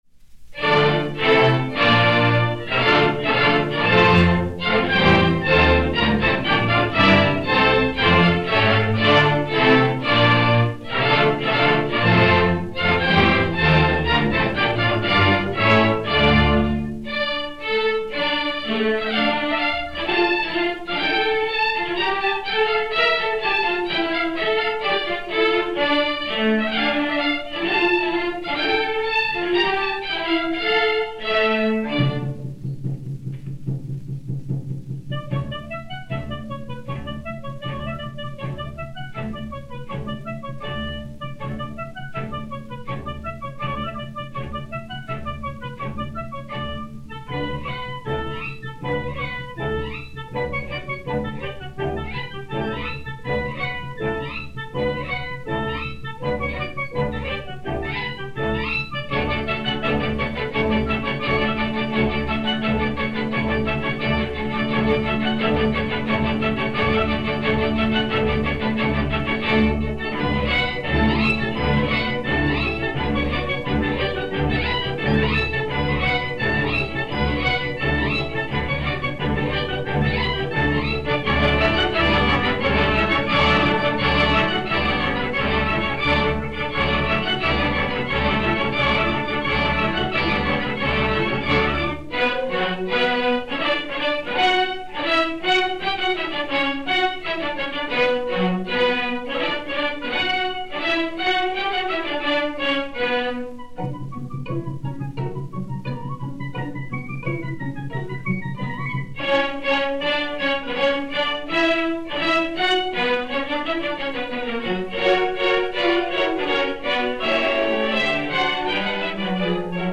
Orchestre Symphonique